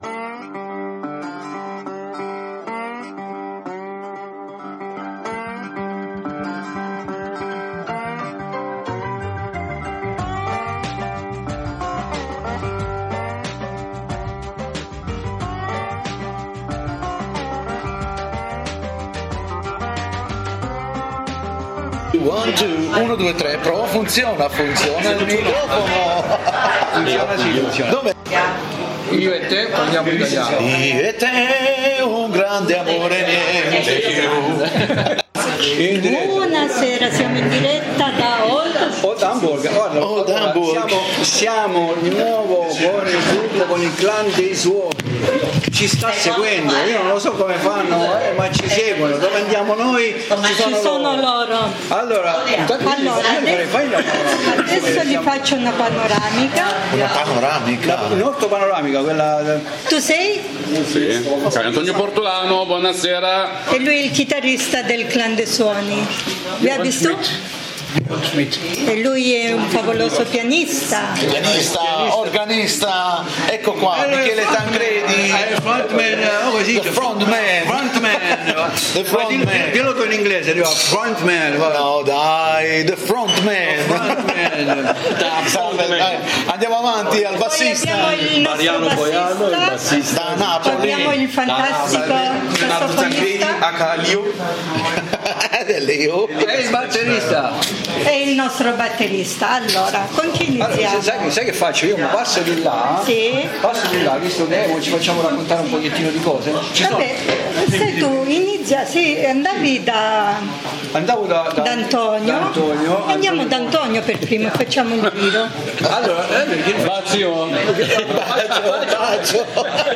SIAMO PRONTI A SENTIRE IL CLAN DEI SUONI PRIMA DEL CONCERTO